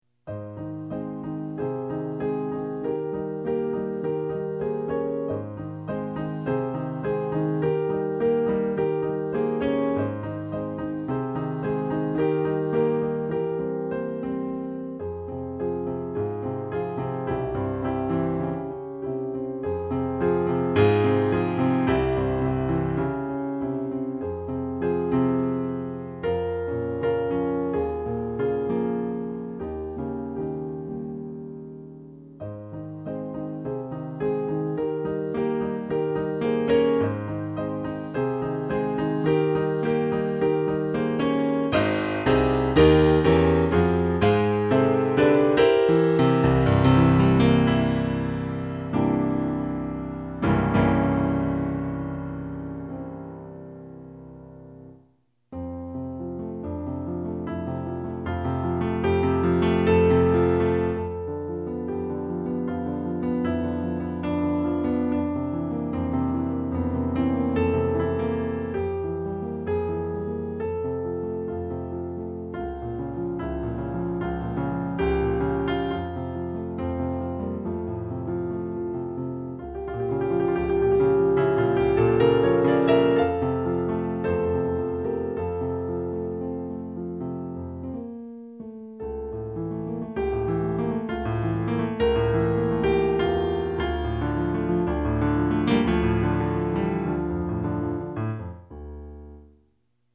A flat major